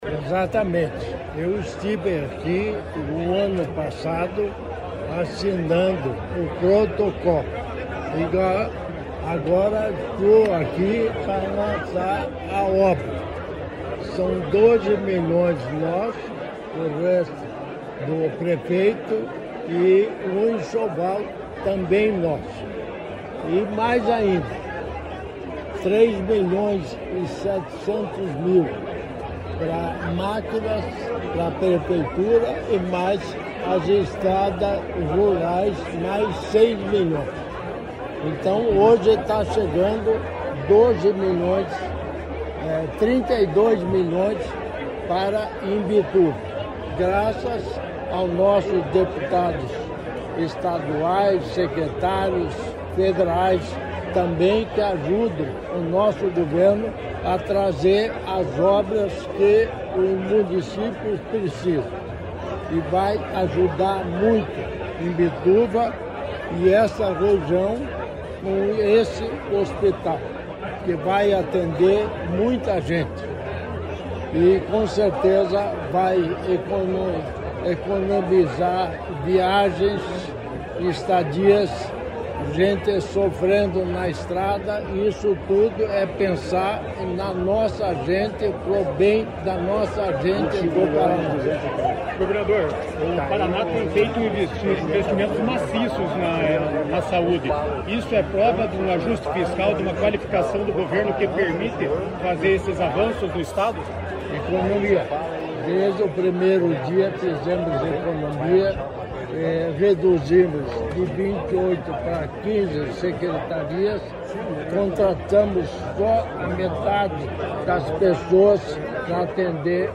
Sonora do governador em exercício Darci Piana sobre a autorização da obra do Hospítal Municipal de Imbituva